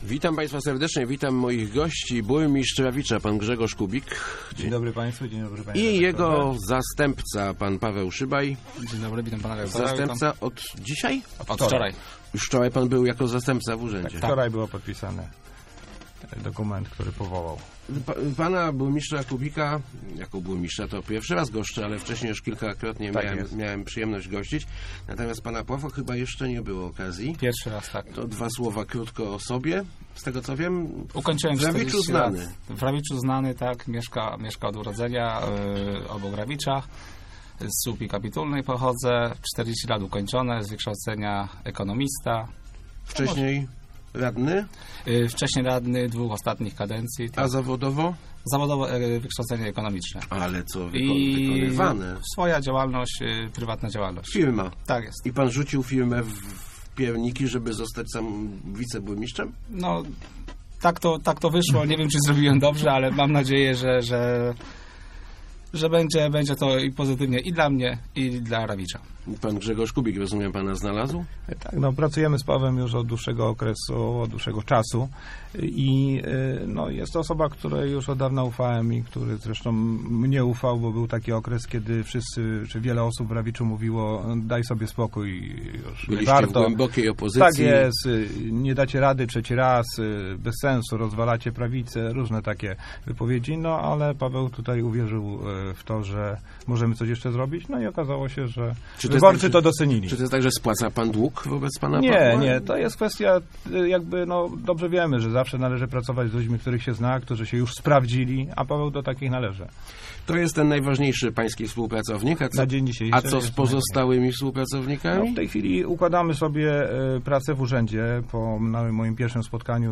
Stan gminy jest całkiem dobry - mówi burmistrz Rawicza Grzegorz Kubik, który wraz z zastępcą Pawłem Szybajem był gościem Rozmów Elki.